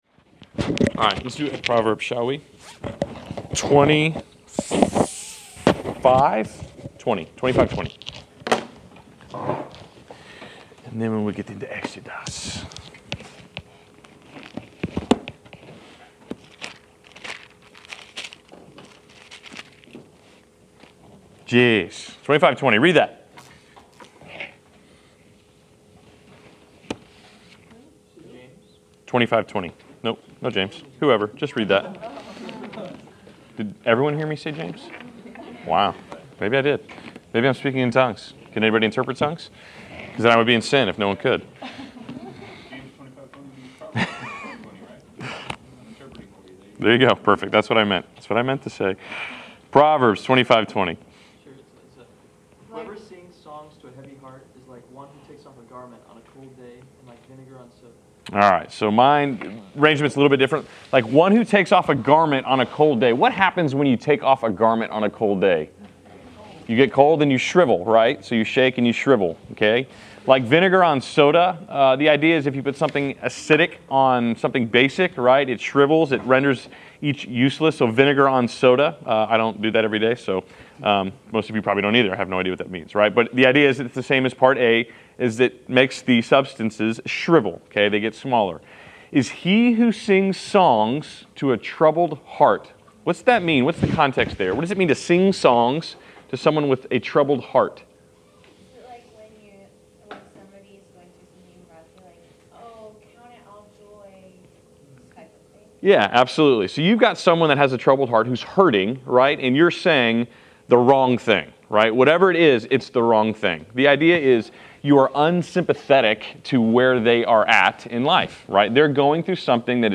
Class Session Audio